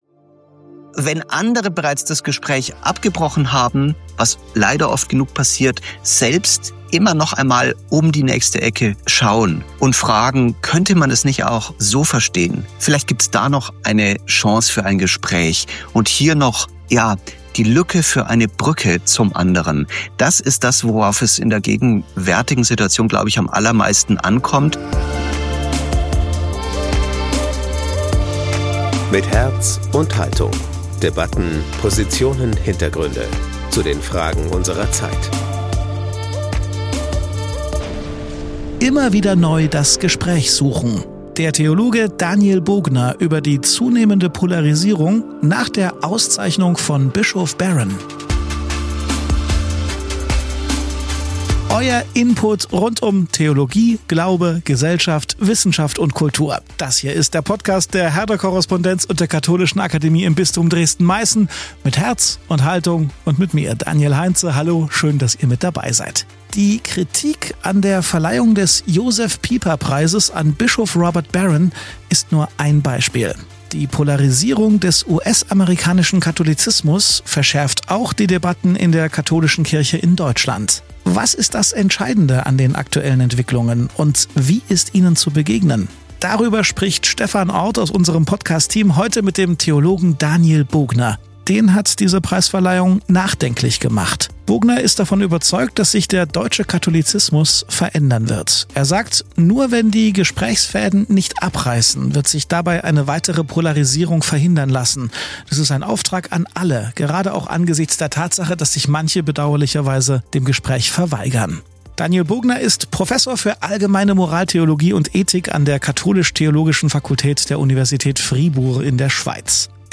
Darüber sprachen wir mit dem Theologen